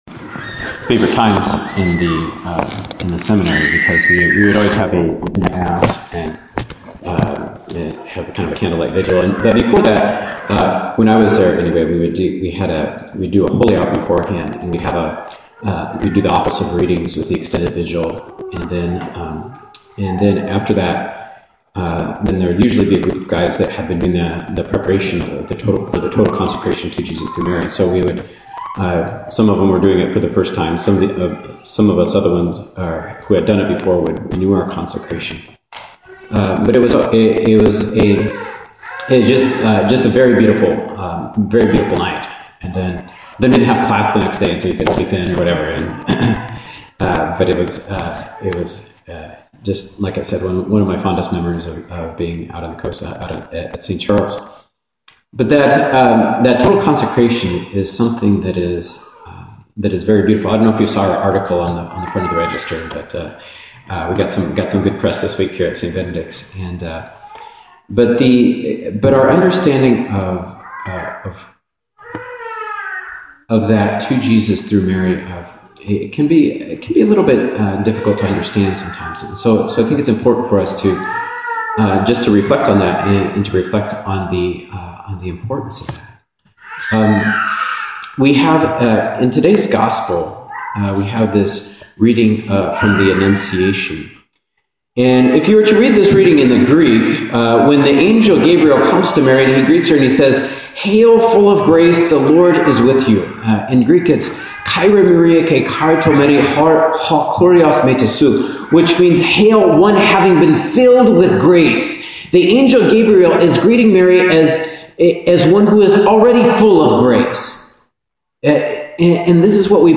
2015 Homilies